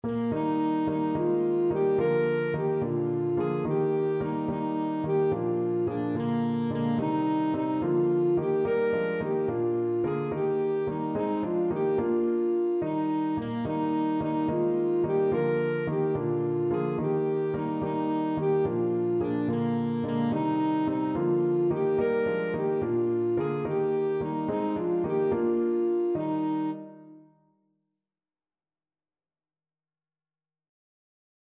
Alto Saxophone
6/8 (View more 6/8 Music)
Allegro . = c. 72 (View more music marked Allegro)